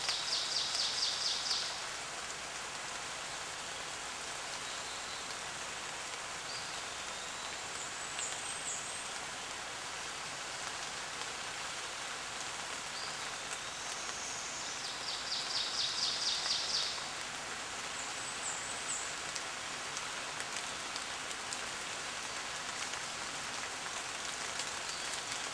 The Sounds of Weather.
- Irish proverb Loud Thunder Soft Rolling Thunder Gentle Rain All the audio above was recorded in Western North Carolina.
mayrainbeaverdampond.wav